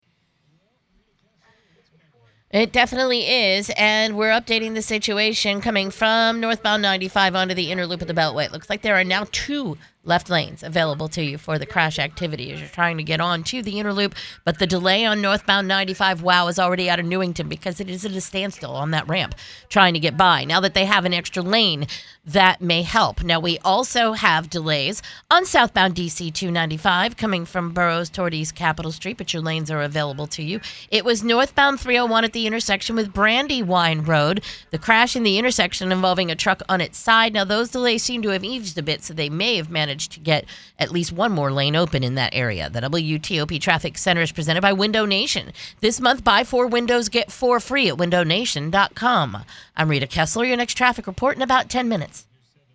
Recent Traffic Report.